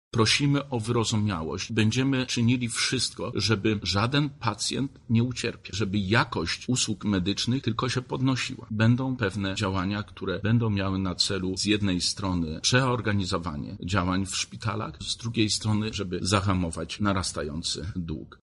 Realizacja nowych założeń może na początku być trochę uciążliwa – mówi Zbigniew Wojeciechowski, wicemarszałek województwa lubelskiego.